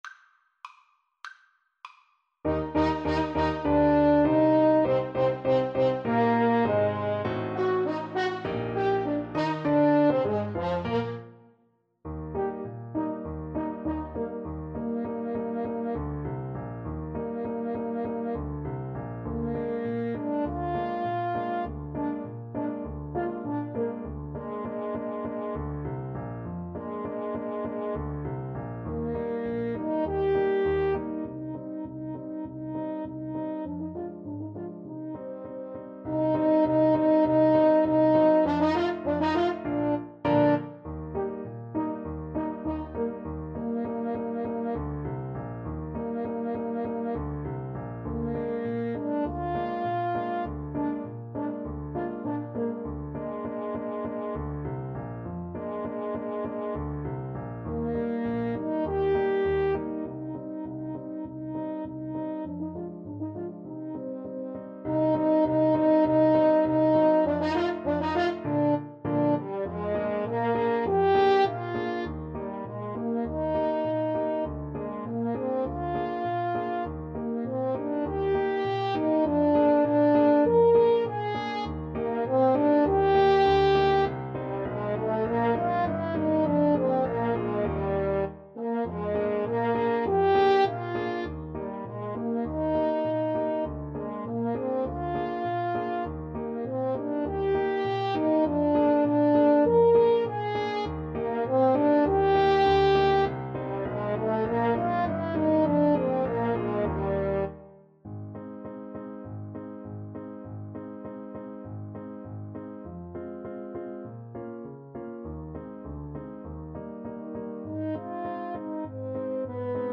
Marziale = c. 100